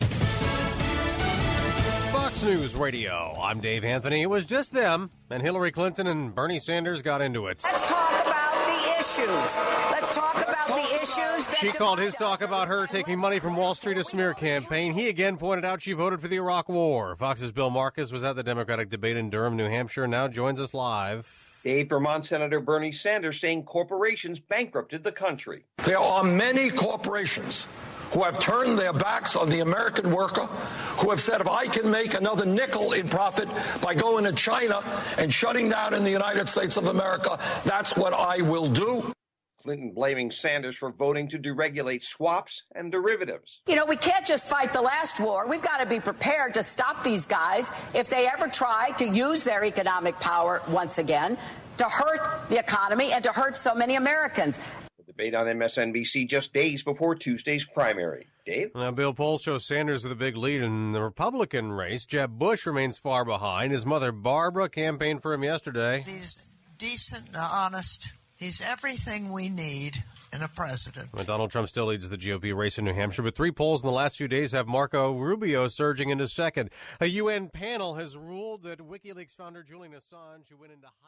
(MANCHESTER, NH) FEB 5 – 8AM LIVE –